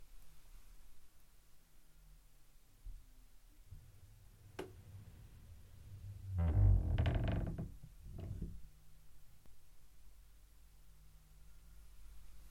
Close/Left wardrobe Door
Duration - 12s Environment - Bedroom, a lot of absorption with bed linen and other furnishings.
Description - Wooden, Door slowly dragged shut, creaks.